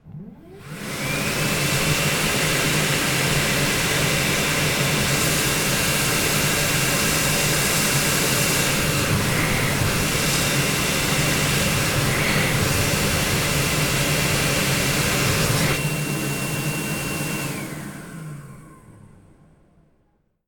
トイレのエアータオル１
hand_dryer1.mp3